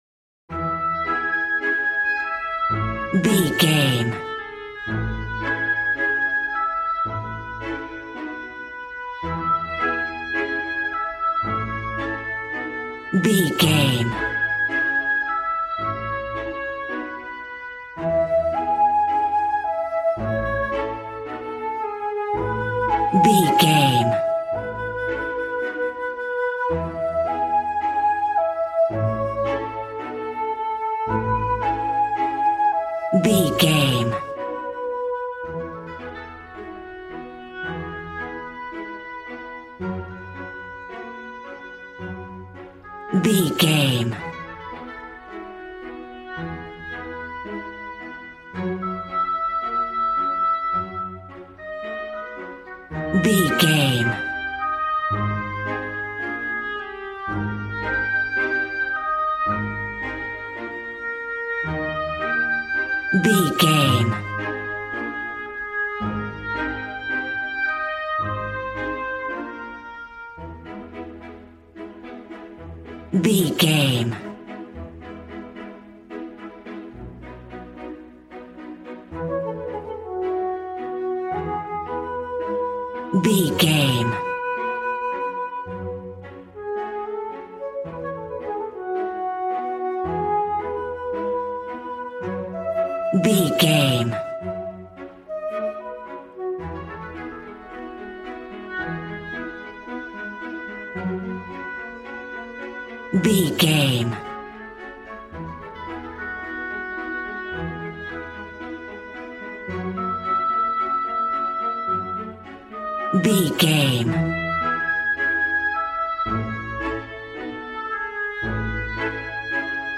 A warm and stunning piece of playful classical music.
Regal and romantic, a classy piece of classical music.
Ionian/Major
A♭
regal
piano
violin
strings